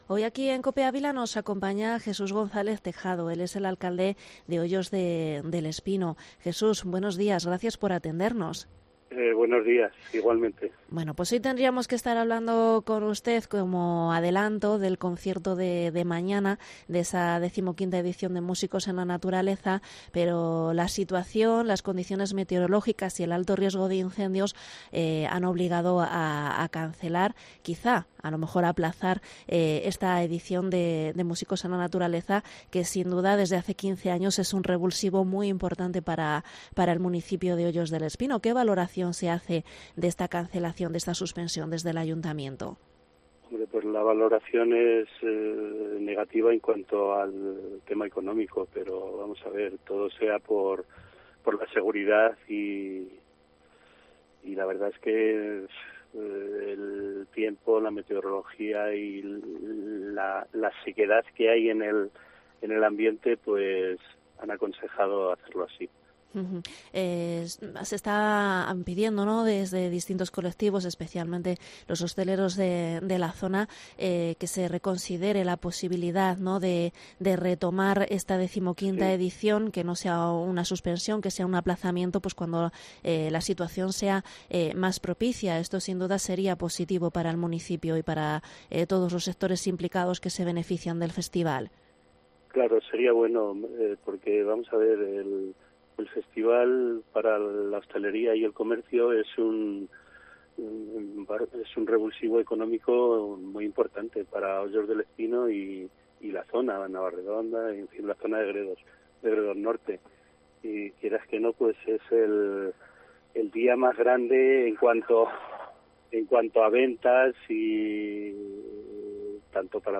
Entrevista alcalde de Hoyos del Espino sobre la suspensión de Músicos en la Naturaleza